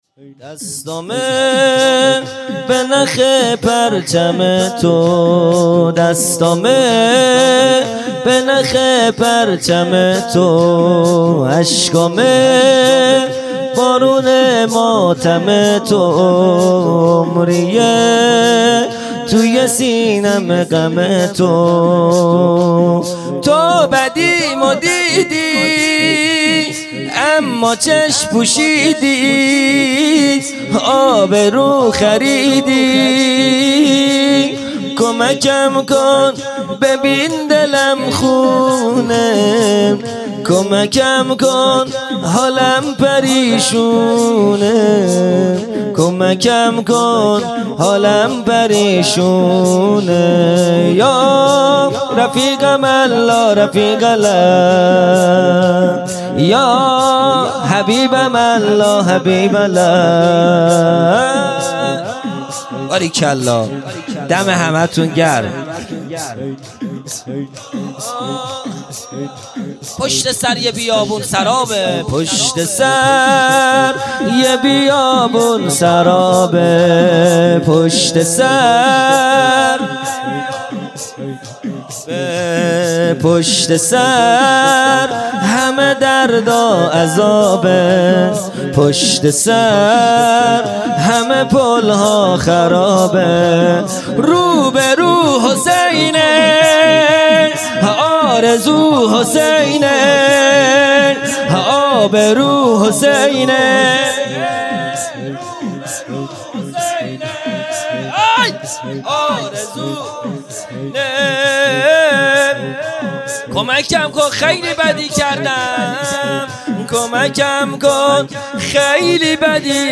خیمه گاه - هیئت بچه های فاطمه (س) - شور | دستامه به نخ پرچم تو
جلسۀ هفتگی